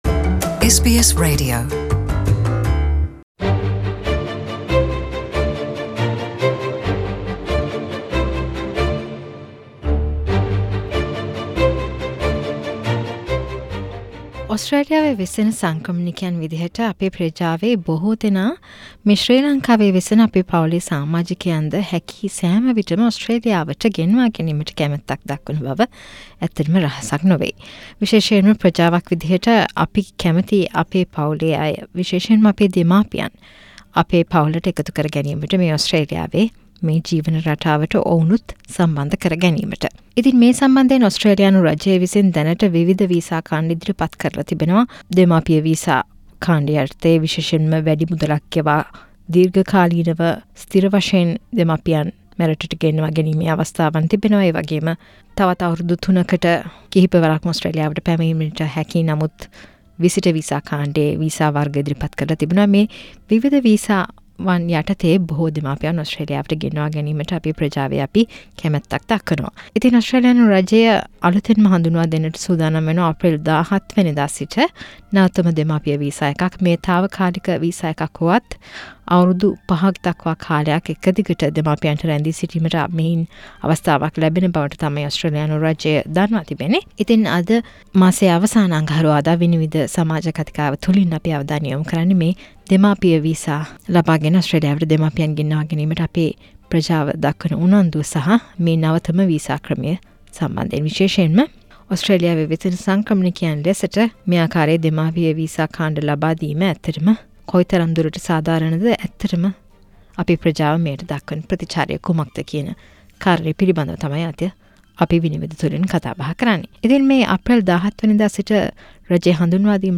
ඕස්ට්‍රේලියාවේ වෙසෙන අයට දෙමාපියන් මෙරටට ගෙන්වා ගැනීමට ඇති ක්‍රම කෙතරම් සාධාරණද? SBS සිංහල 'විනිවිද' මාසික සමාජ මත විමසුම